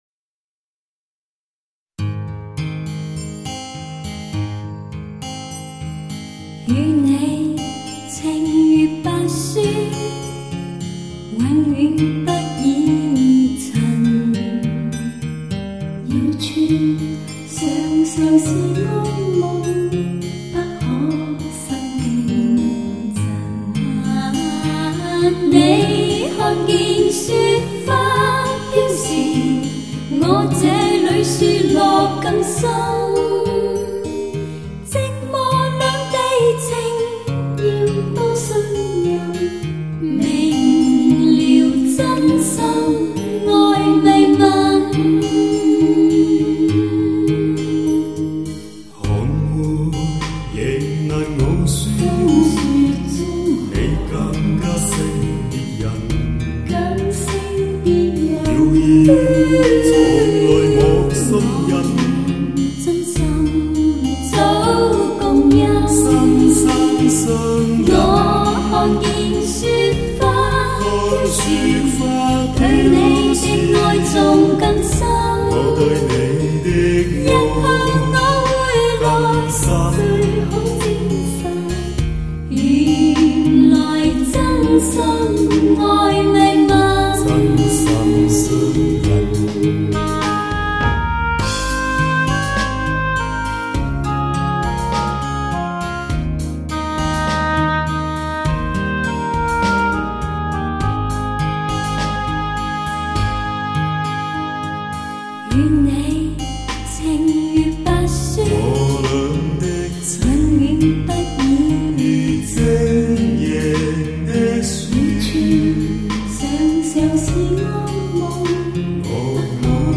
类型: HIFI试音
，身临其境，广阔无边，高端豪华，HIFI最高境界。